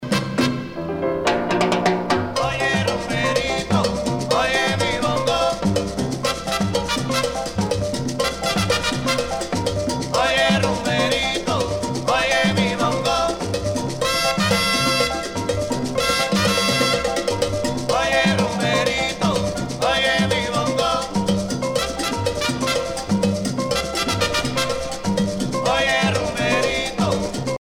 danse : guaracha
Pièce musicale éditée